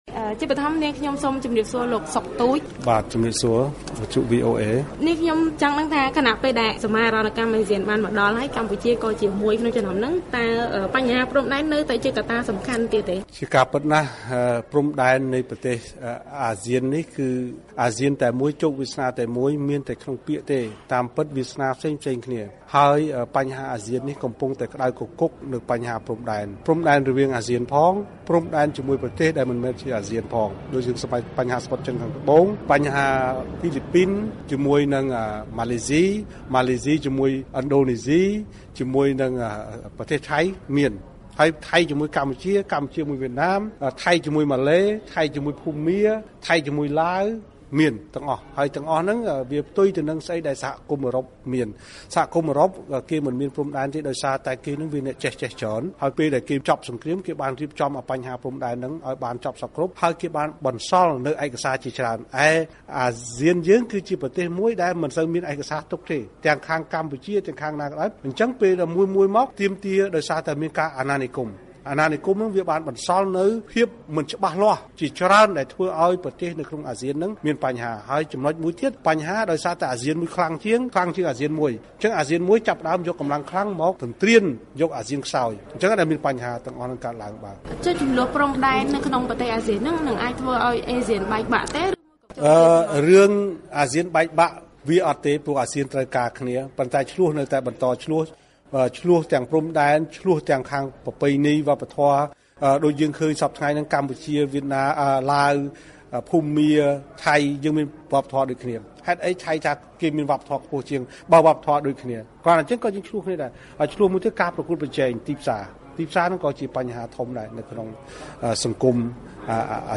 បទសម្ភាសន៍ VOA